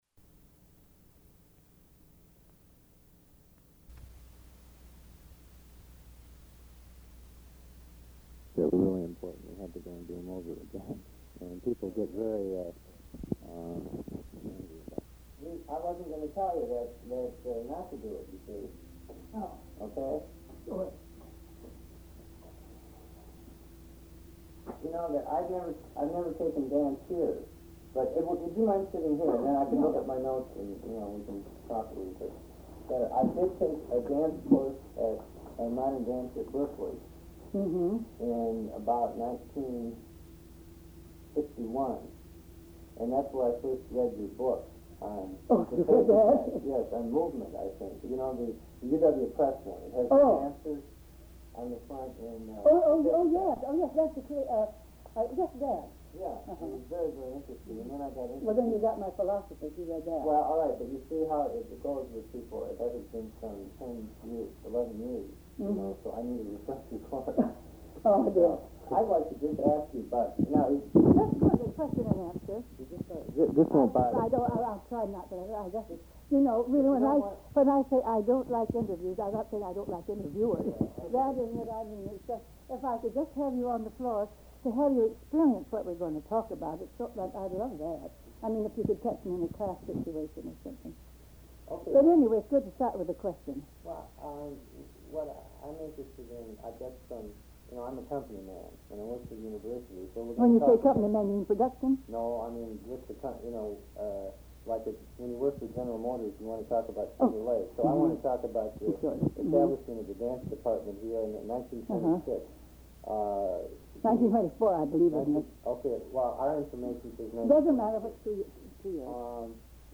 Oral History Interview: Margaret H'Doubler (609)